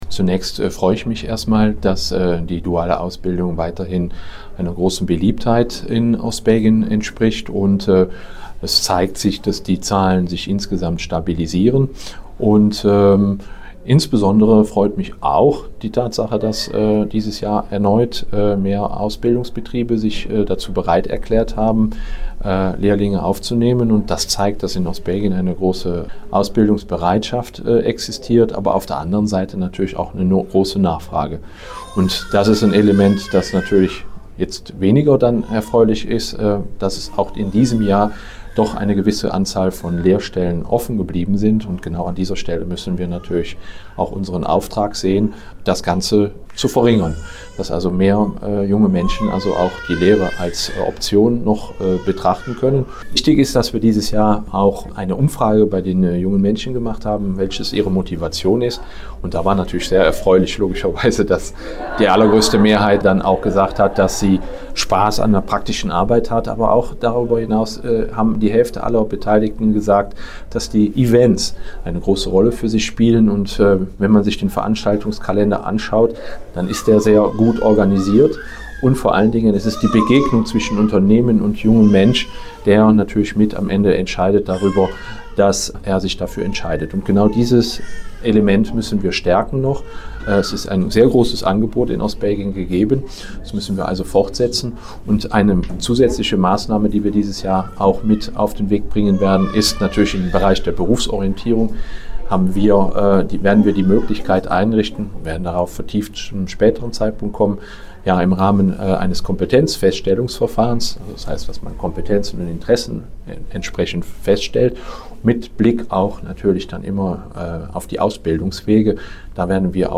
Ausbildungsminister Jérôme Franssen mit den Einzelheiten.